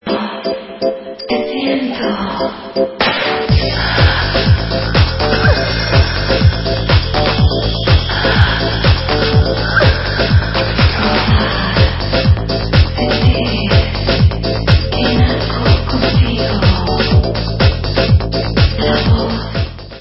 sledovat novinky v oddělení Disco